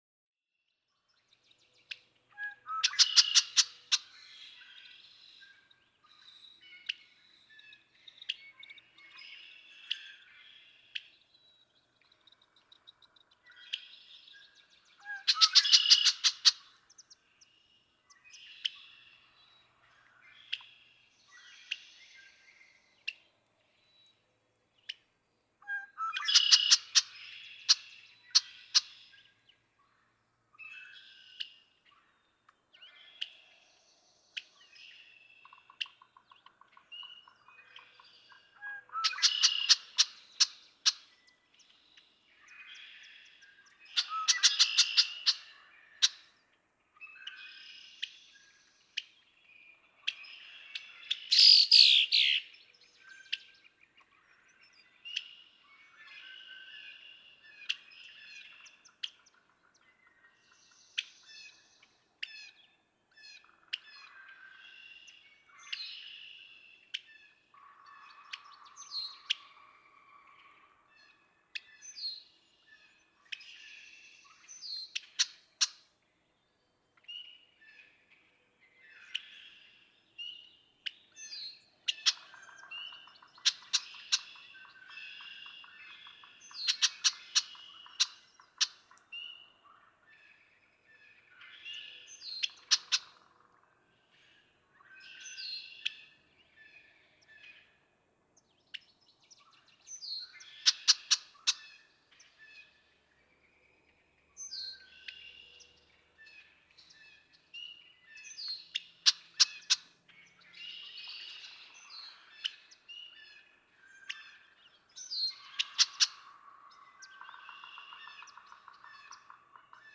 Red-winged blackbird
♫56. A little after sunrise, the female has now joined her mate; she calls in the foreground, initially responding with a loud chit-chit-chit-chit call to each of the male's songs heard in the background. She eventually utters her aggressive, descending teer-teer-teer-teer call (at 0:51), probably a notice to other females that they're not especially welcome here.
Atlanta, Michigan.
056_Red-winged_Blackbird.mp3